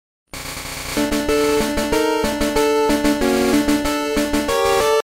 Videogame music and sound effects